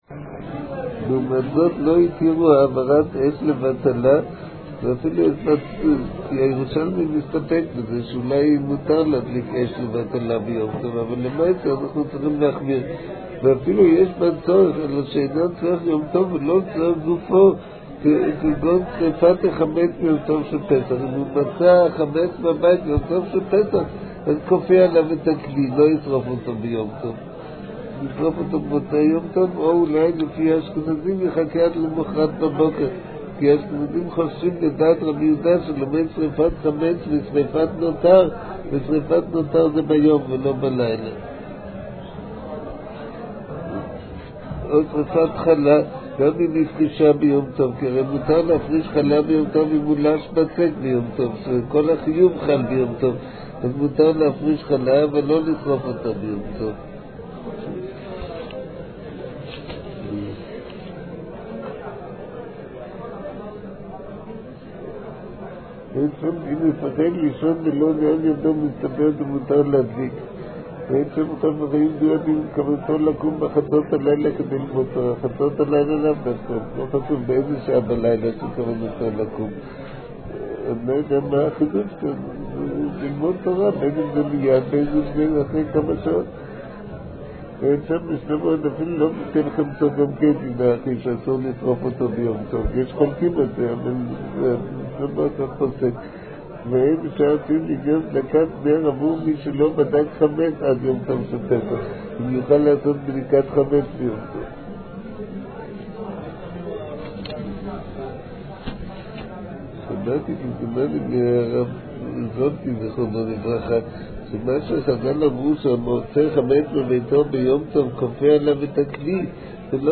מעביר השיעור: מו"ר הרב אביגדר נבנצל